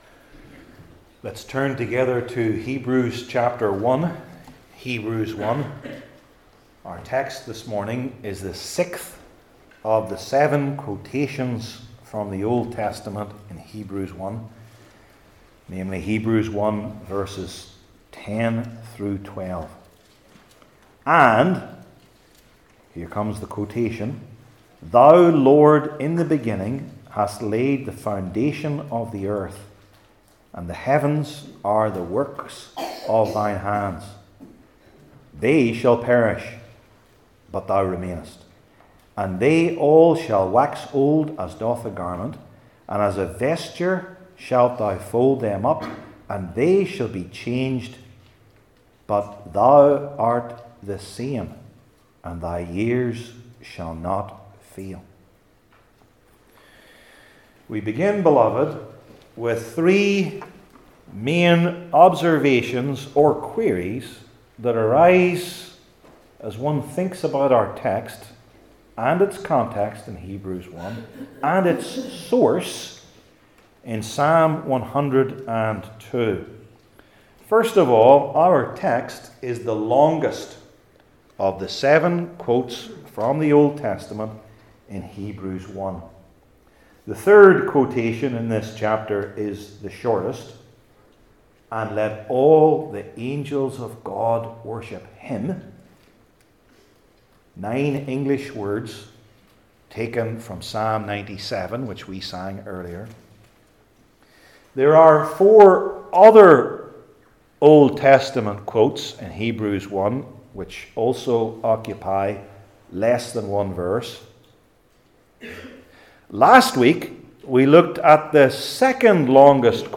Hebrews 1:10-12 Service Type: New Testament Sermon Series I. In Psalm 102 II.